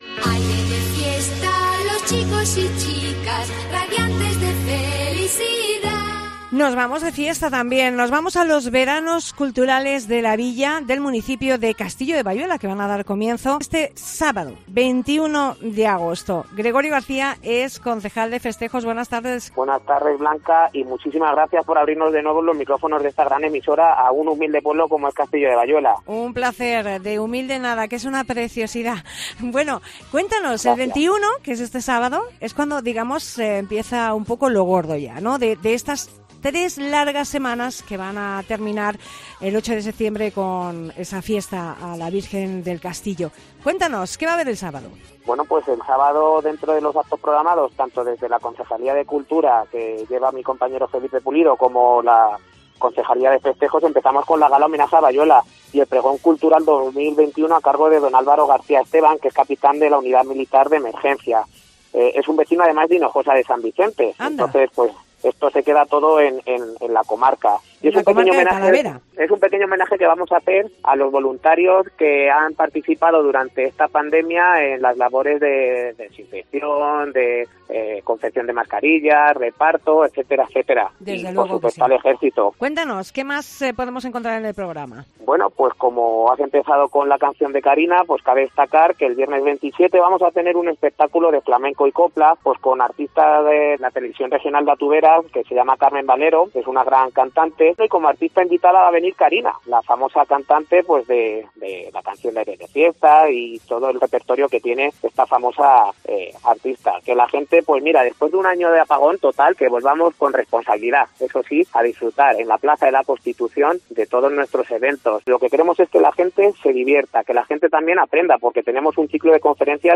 ctv-3md-entrevista-a-gregorio-garca-concejal-de-festejos-de-castillo-de-bayuela